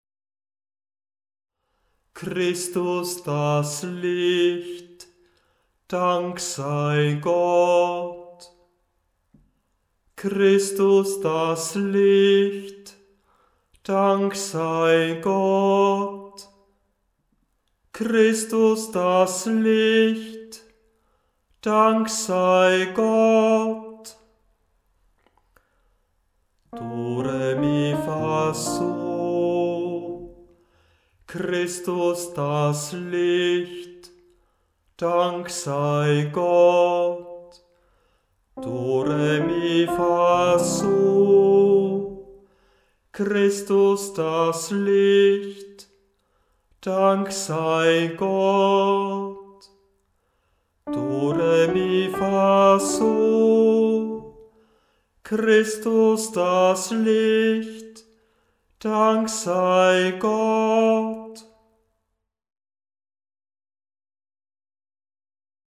Beim Stundengebet übernimmt nach der Einleitung ein Kantor/eine Kantorin das Anstimmen der Gesänge.
Christus, das Licht, Gl 312,1 2 MB mit dreimaliger Steigerung In Namen unseres Herrn Jesus Christus, Dienstebuch S. 94 920 KB erster Ton a wie notiert In Namen unseres Herrn Jesus Christus, Dienstebuch S. 94 958 KB erster Ton g, eine große Sekunde tiefer Im Namen unseres Herrn Jesus Christus, Dienstebuch S. 94 977 KB erster Ton fis, eine kleine Terz tiefer